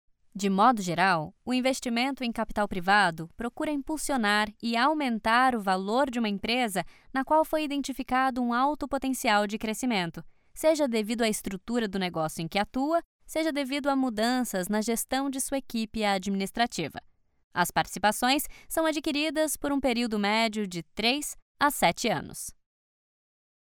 Voces profesionales brasileñas.
locutora Brasil, Brazilian voice over